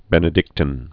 (bĕnĭ-dĭktĭn, -tēn)